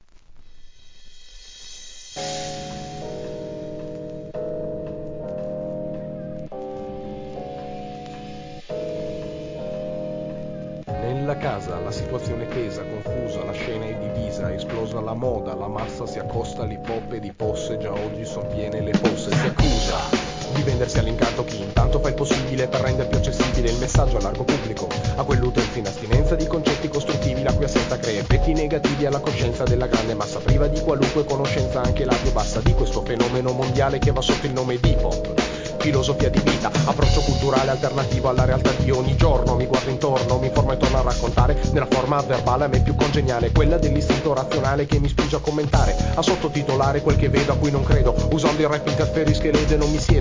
イタリアンHIP HOP!!